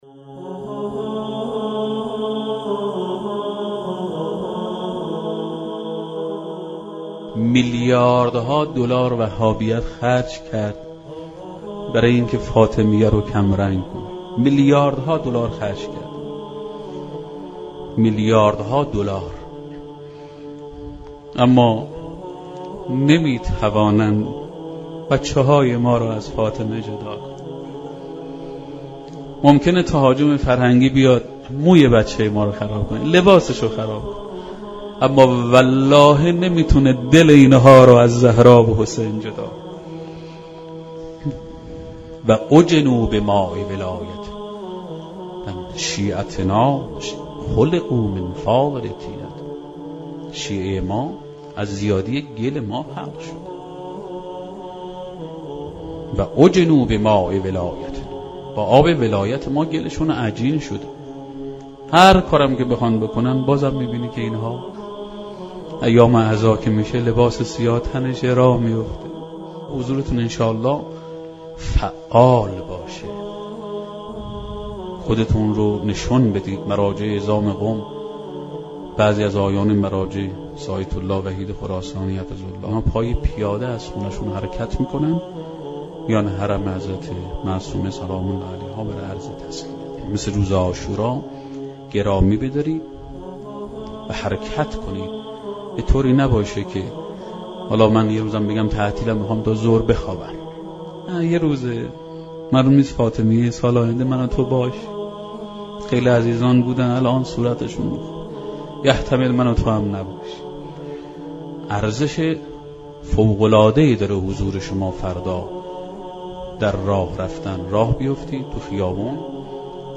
سخنرانی شنیدنی درباره محبوبیت حضرت فاطمه زهرا(س